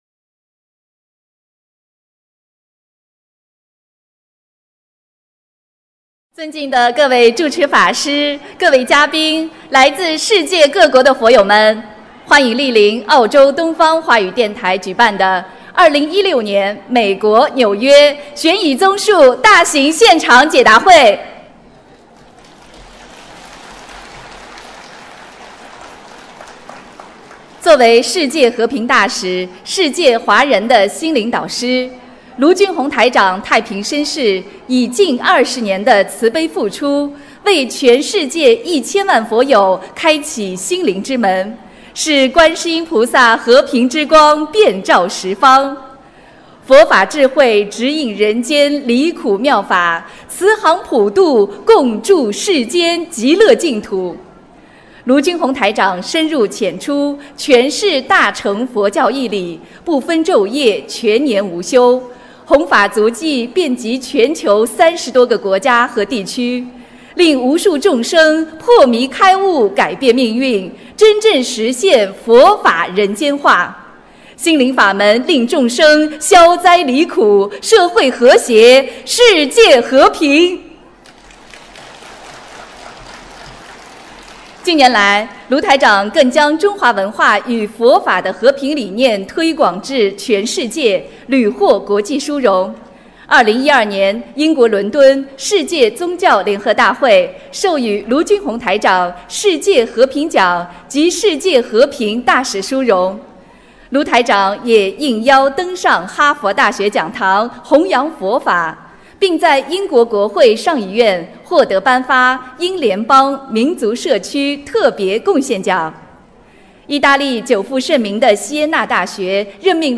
2016年9月18日美国纽约解答会开示（视音文图） - 2016年 - 心如菩提 - Powered by Discuz!